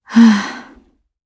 Ripine-Vox_Sigh_jp_b.wav